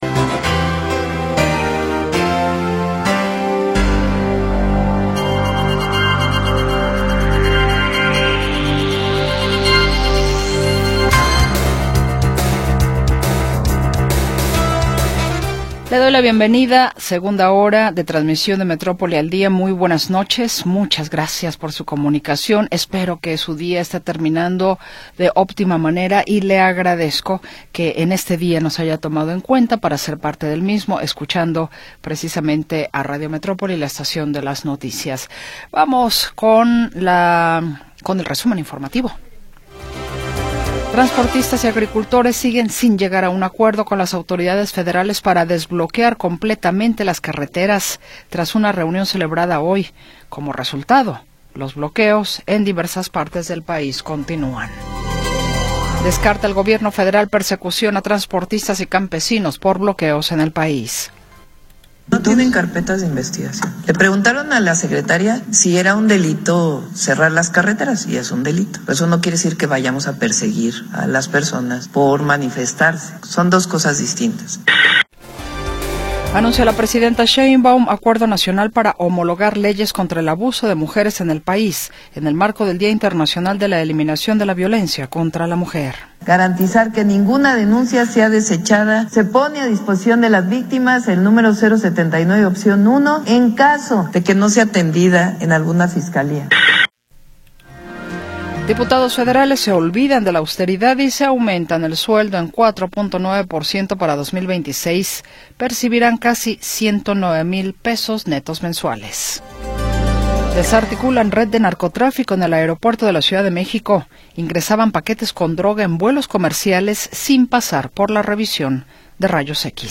Segunda hora del programa transmitido el 25 de Noviembre de 2025.